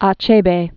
(ä-chābā), Chinua 1930-2013.